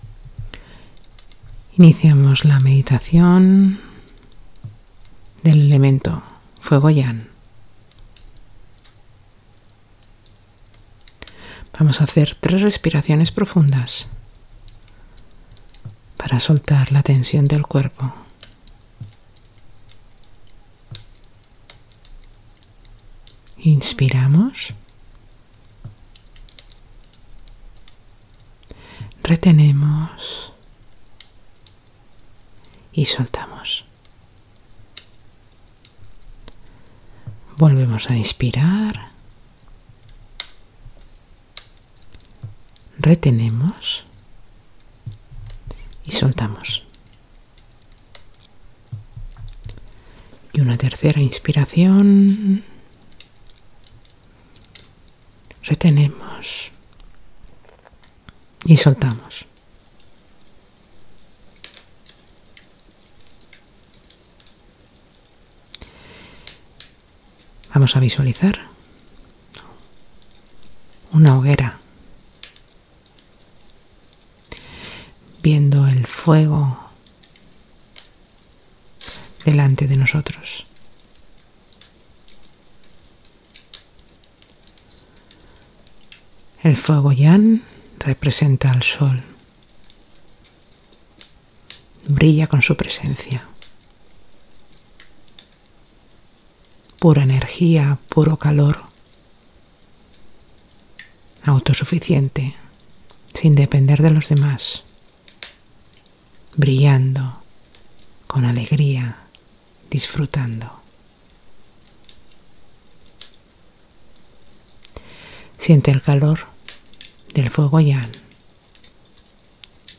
Meditación – Fuego yang
RELAJANTE